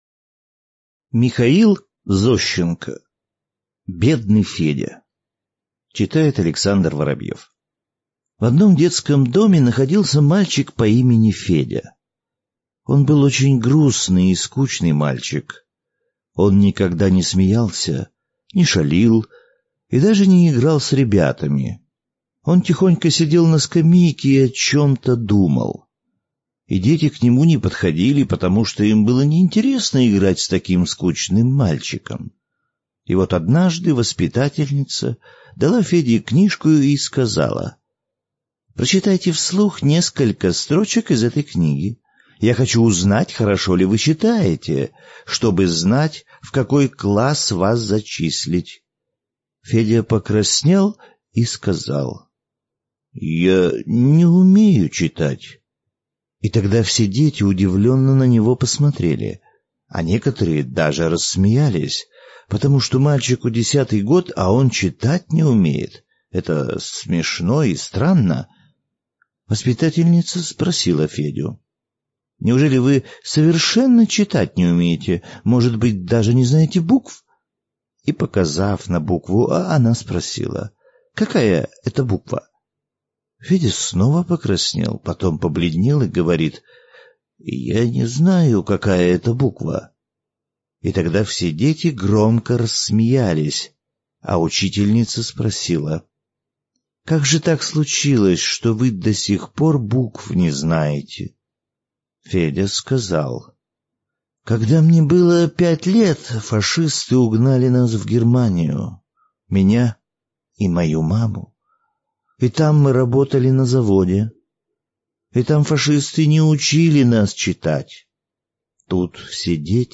Аудиорассказ «Бедный Федя»